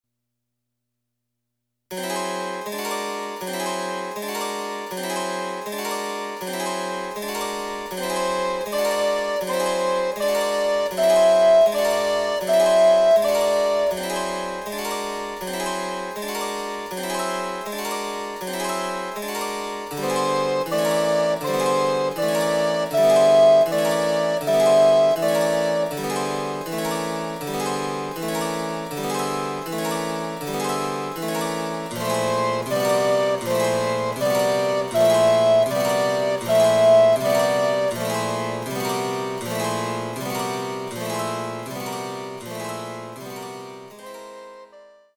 アルトリコーダー独奏（チェンバロ伴奏）
・各曲につき、リコーダー演奏例とチェンバロ伴奏